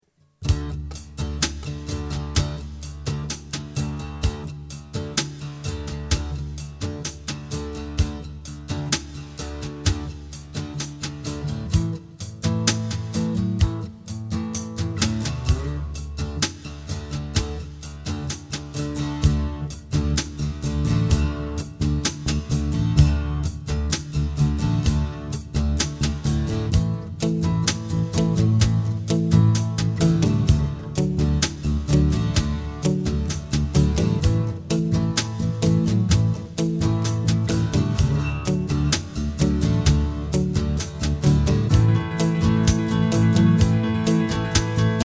Listen to the instrumental track.